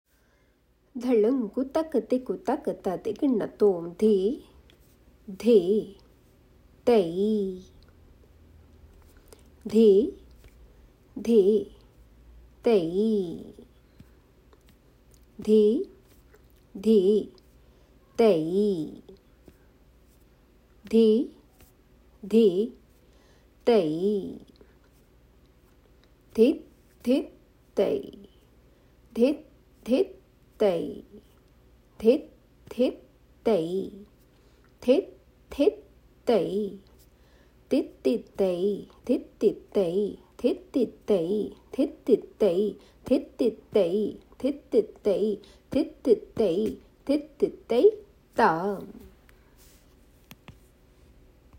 Here is the sollukettu (Bols or syllables) for first and the sixth variation. The Bols are “Dhit Dhit Tai”. It is sung in three speed for the purpose of practice.